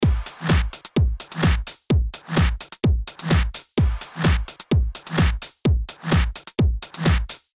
Here’s a snippet of the drum loop as-is.